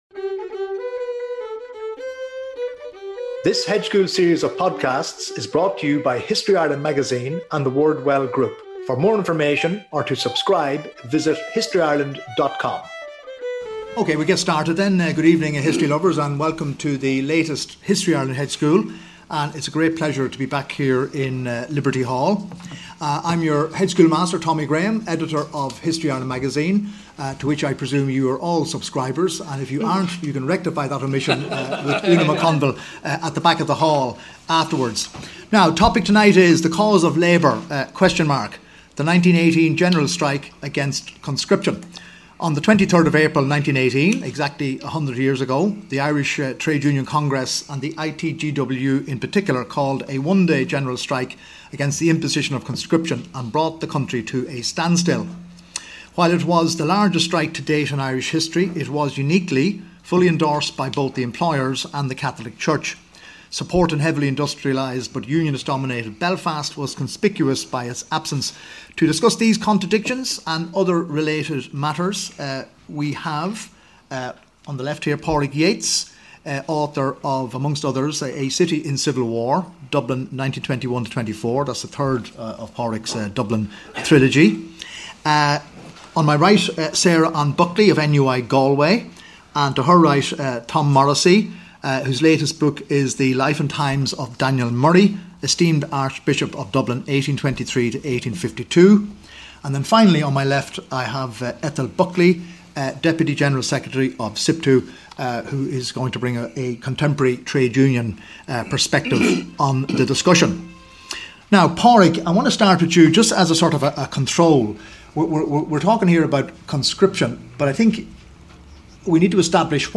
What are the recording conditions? Recorded on: Monday, 23 APRIL 2018 at 7pm. Venue: @ Liberty Hall, Dublin 1